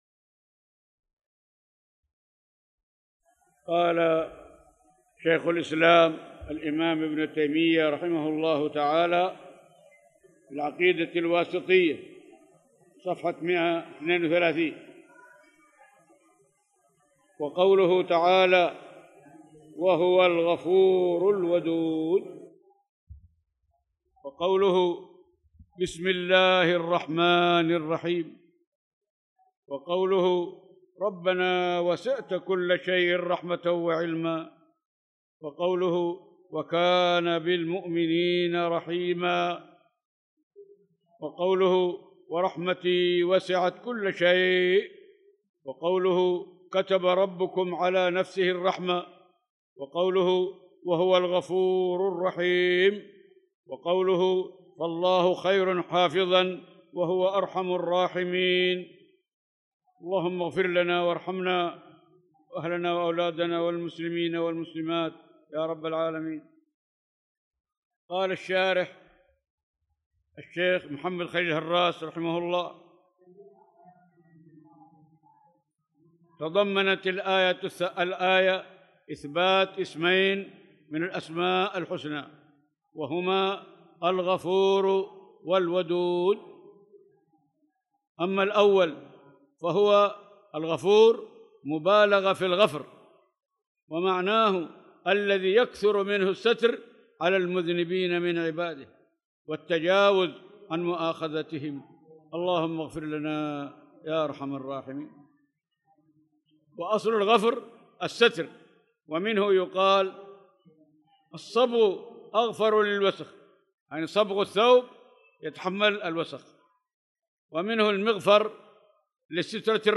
تاريخ النشر ١٨ ذو القعدة ١٤٣٧ هـ المكان: المسجد الحرام الشيخ